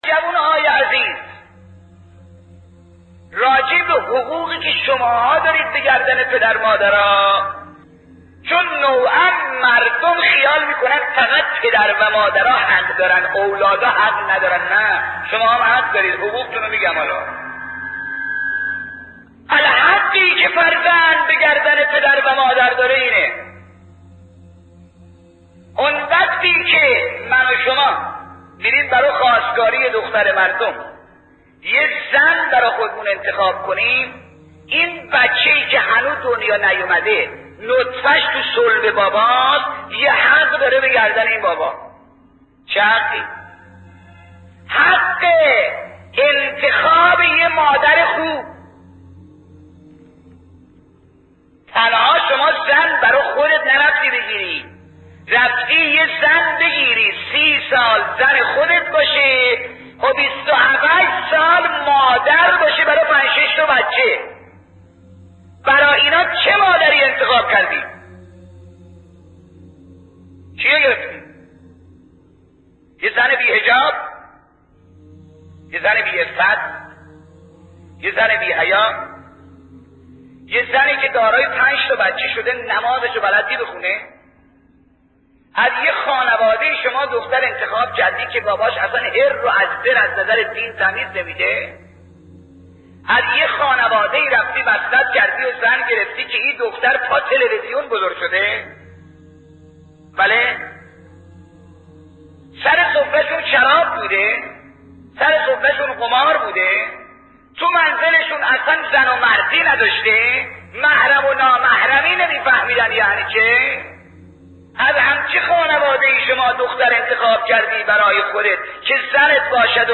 دانلود وظایف والدین نسبت به فرزندان سخنران : حاج آقا کافی (ره) حجم فایل : 5 مگابایت زمان : 9 دقیقه توضیحات : موضوعات : دسته بندی ها حاج آقا کافی (ره) والدین